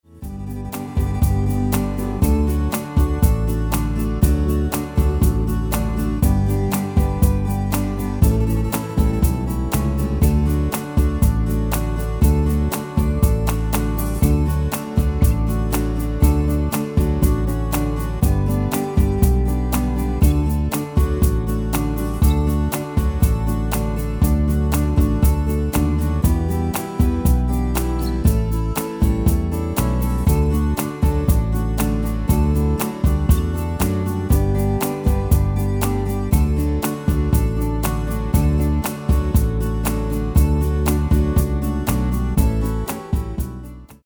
Demo/Koop midifile
Genre: Pop & Rock Internationaal
Toonsoort: D
- GM = General Midi level 1
- Géén vocal harmony tracks
Demo = Demo midifile